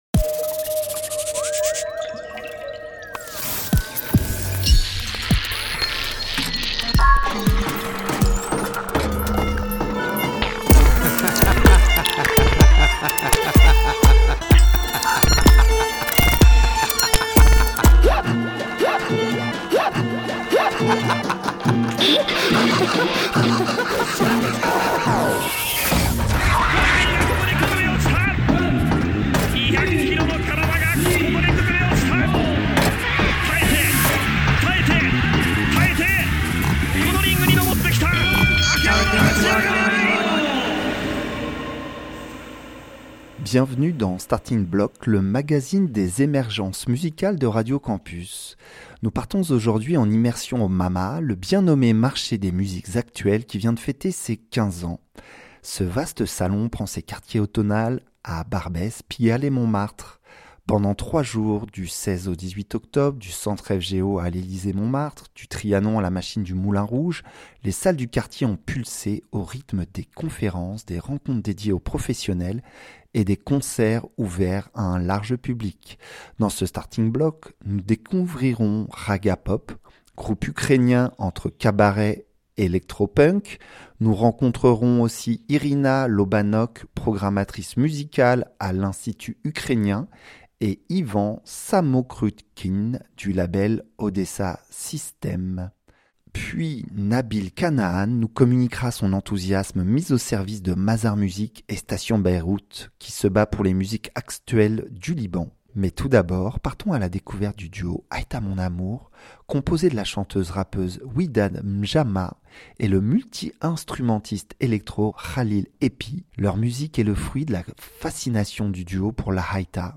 Magazine Découvertes musicales Éclectique